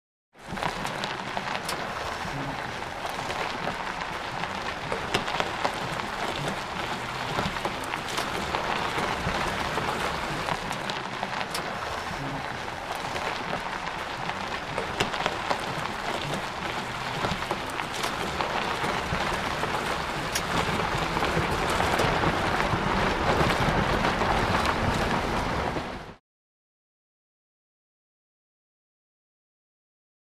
Tires, Dirt / Gravel Surface Steady